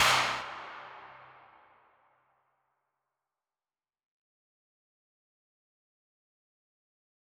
MDMV3 - Hit 6.wav